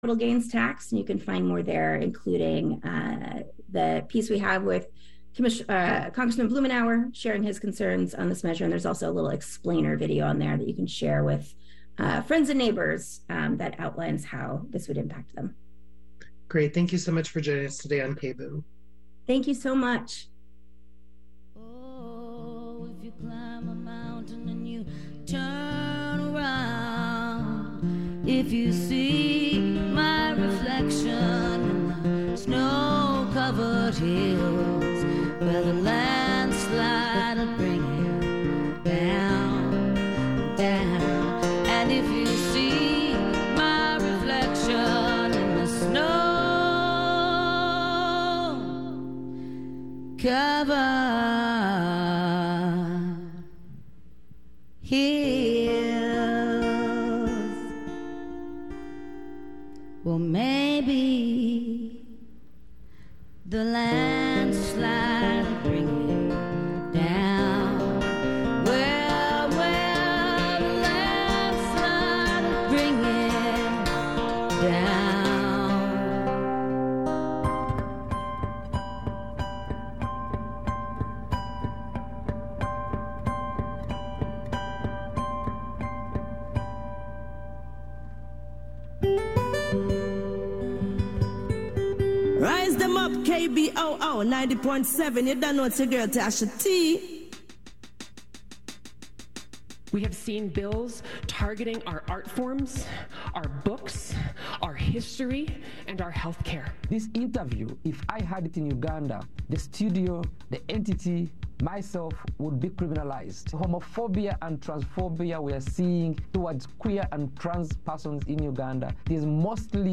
This Way Out is the only internationally distributed weekly LGBTQ radio program, currently airing on some 200 local community radio stations around the world. The award-winning half-hour magazine-style program features a summary of some of the major news events in or affecting the queer community (NewsWrap), in-depth coverage of major events, interviews with key queer figures, plus music, literature, entertainment — all the information and culture of a community on the move!